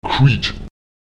Lautsprecher kit [k¨it] sitzen